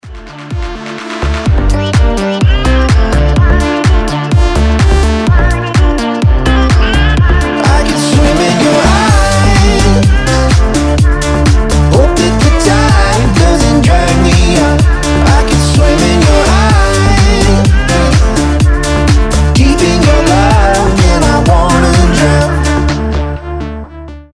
• Качество: 320, Stereo
мужской голос
deep house
EDM
house